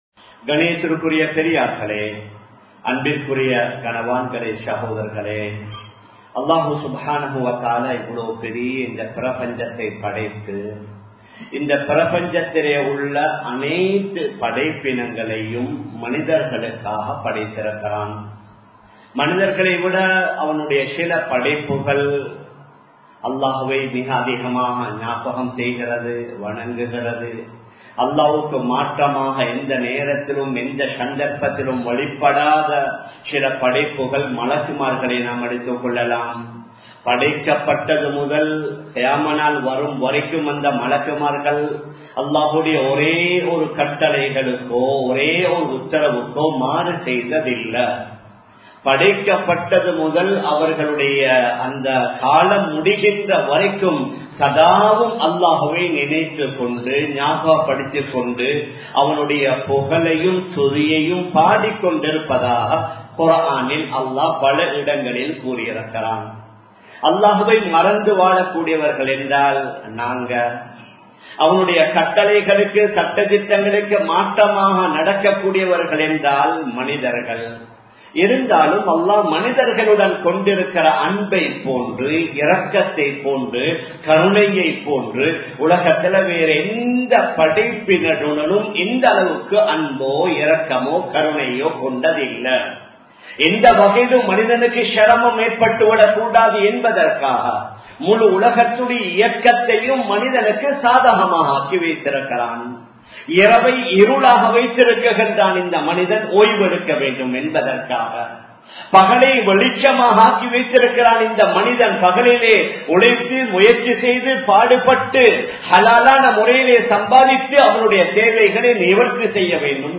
அல்லாஹ் | Audio Bayans | All Ceylon Muslim Youth Community | Addalaichenai